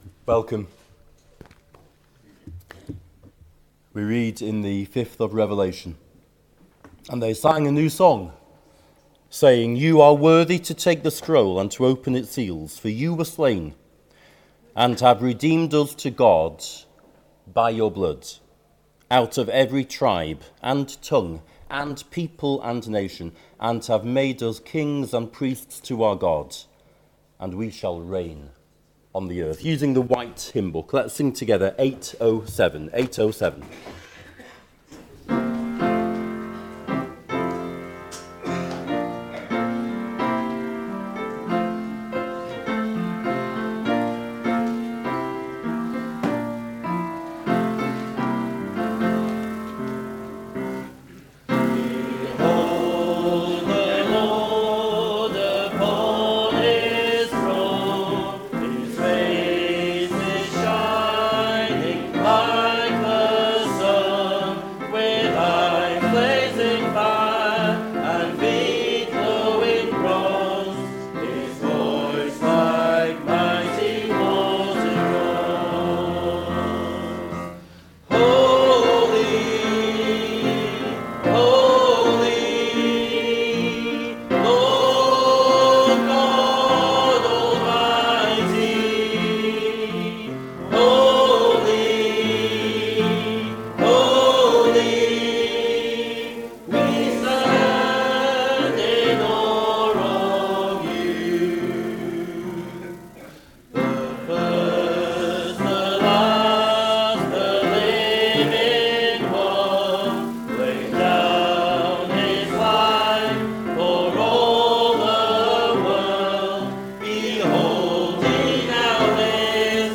Below is audio of the full service.
2025-10-12 Morning Worship If you listen to the whole service on here (as opposed to just the sermon), would you let us know?